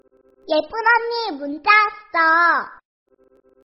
Nada notifikasi Kaka cantik ada pesan!! versi Korea
Kategori: Nada dering